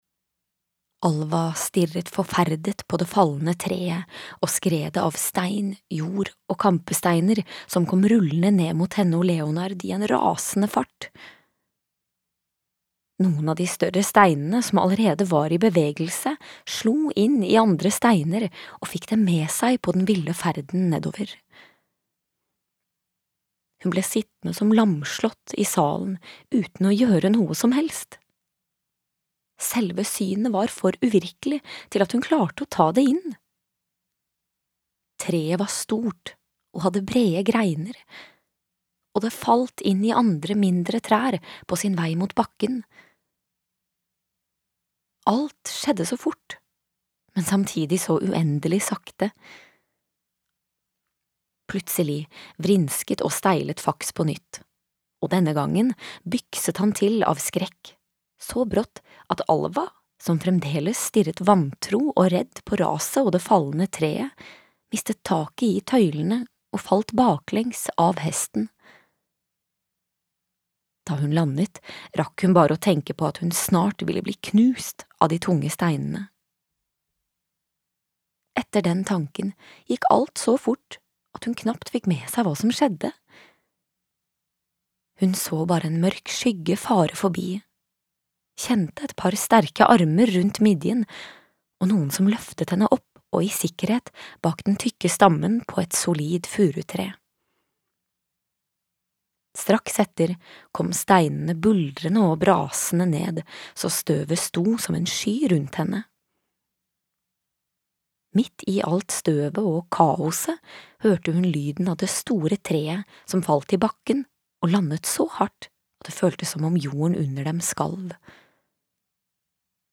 Arvesynd (lydbok) av Stine Langtangen